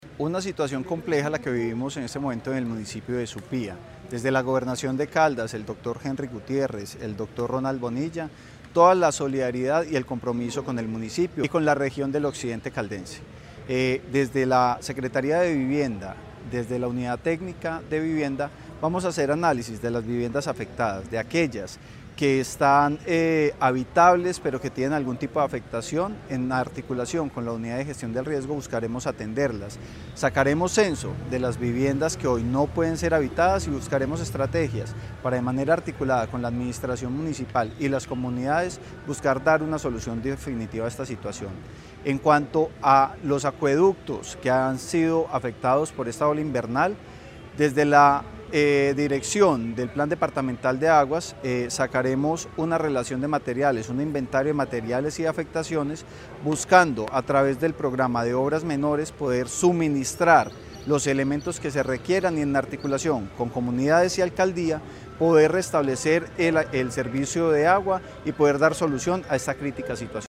Francisco Javier Vélez Quiroga, secretario de Vivienda y Territorio de Caldas